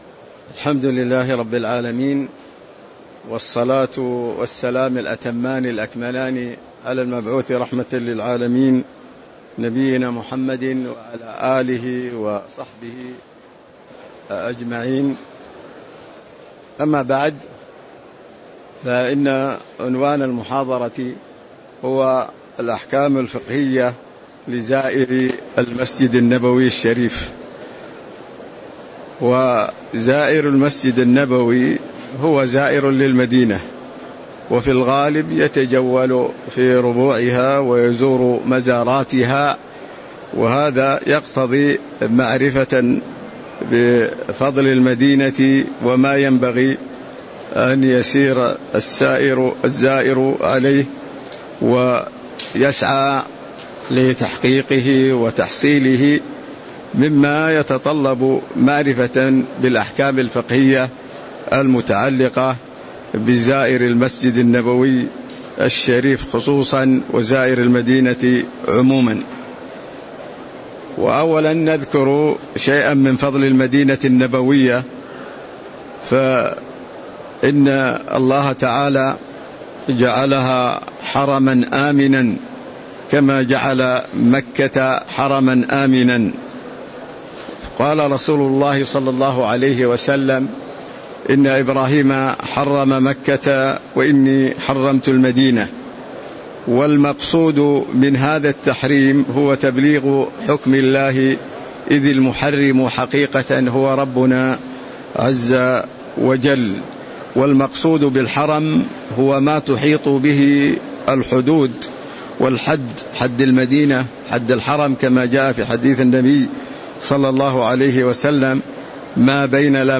تاريخ النشر ١٣ رمضان ١٤٤٤ هـ المكان: المسجد النبوي الشيخ